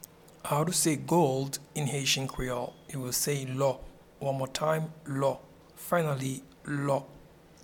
Pronunciation and Transcript:
Gold-in-Haitian-Creole-Lo.mp3